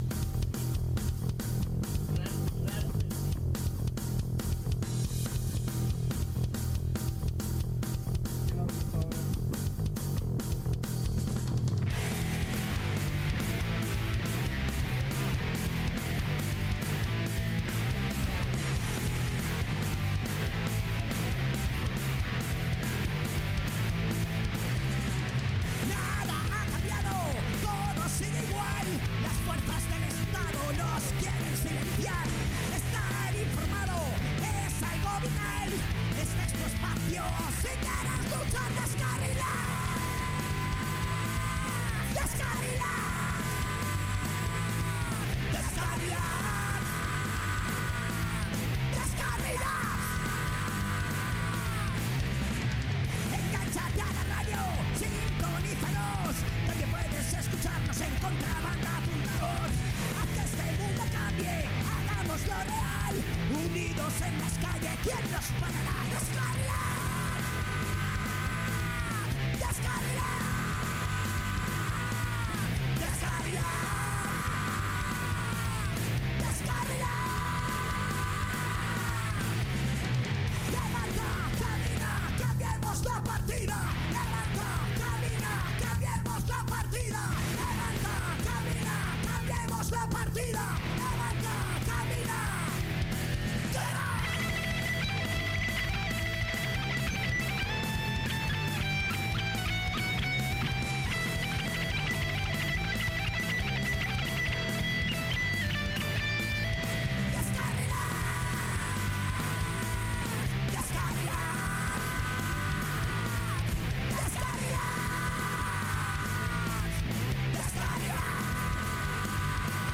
Durante el programa vamos poniendo diferentes musicas relacionadas con la temática de este programa